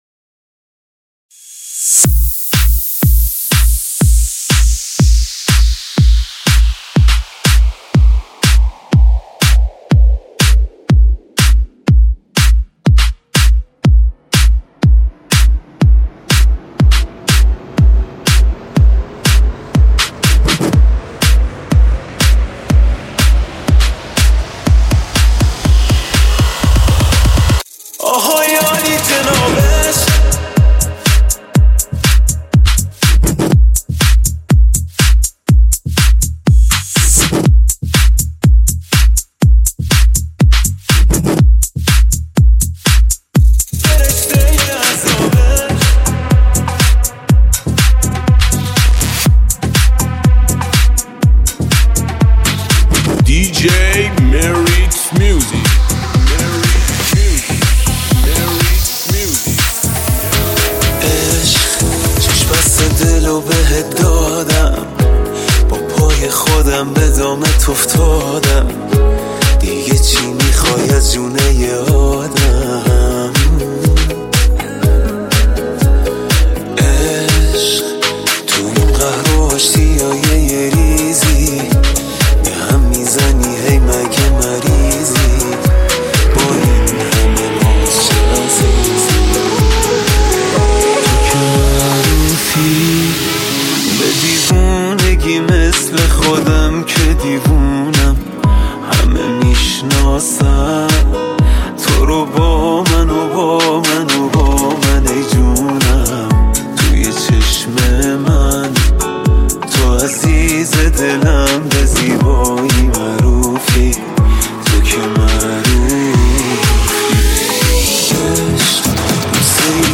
بیس دار و کوبنده
ریمیکس تلفیقی